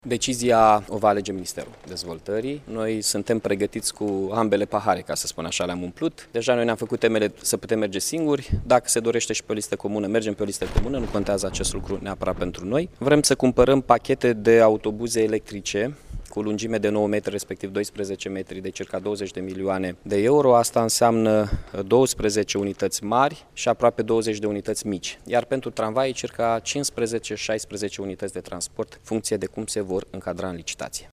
Edilul Mihai Chirica a anunţat, astăzi, într-o conferinţă de presă, că 25 de localităţi din România au depus o aplicaţie la Ministerul Dezvoltării în vederea achiziţionării unor vehicule electrice.